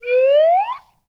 pgs/Assets/Audio/Comedy_Cartoon/whistle_slide_up_06.wav at 7452e70b8c5ad2f7daae623e1a952eb18c9caab4
whistle_slide_up_06.wav